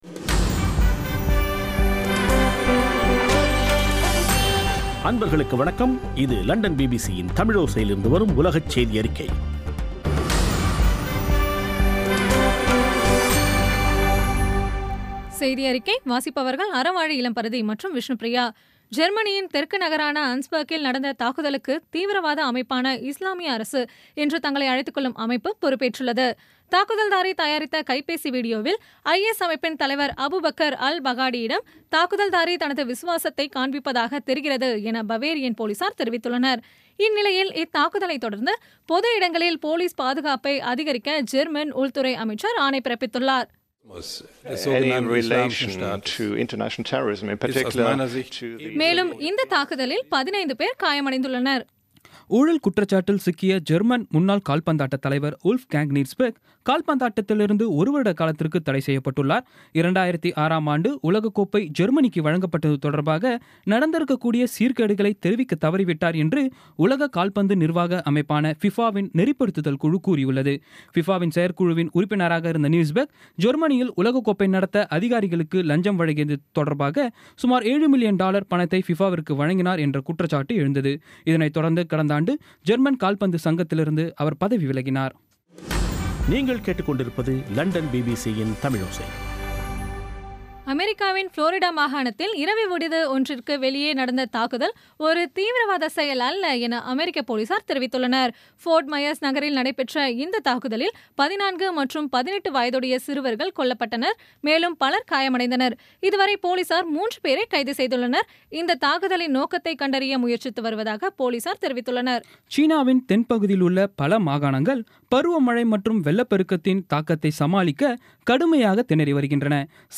இன்றைய (ஜூலை 25ம் தேதி ) பிபிசி தமிழோசை செய்தியறிக்கை